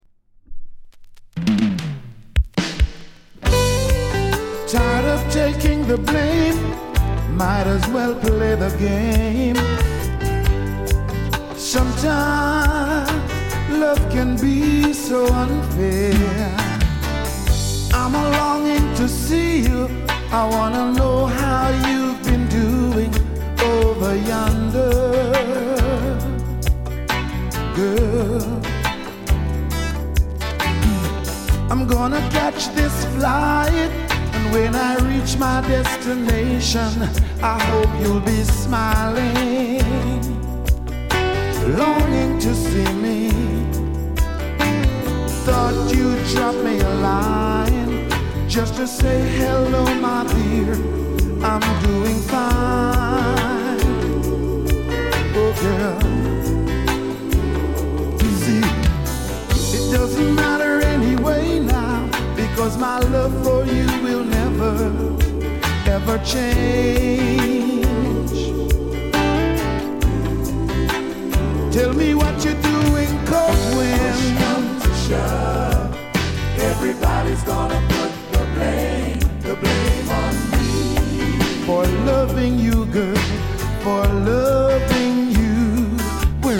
SOUL作品